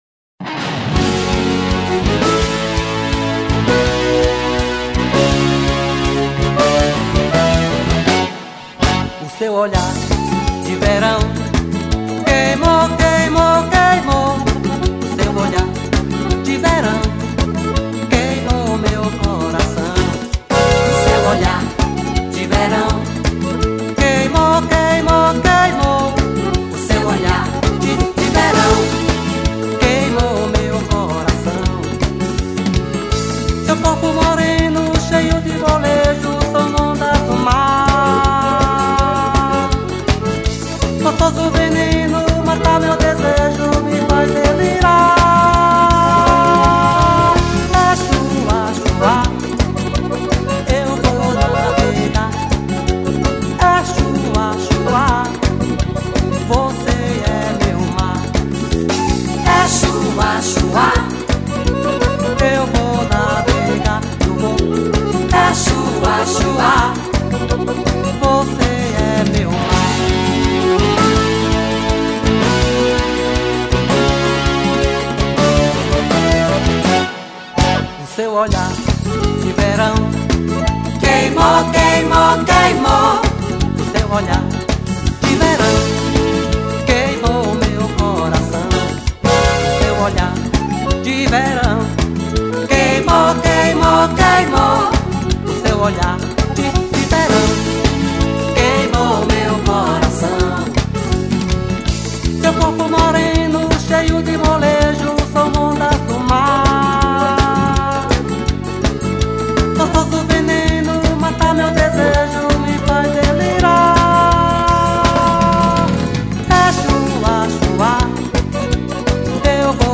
2131   02:53:00   Faixa:     Forró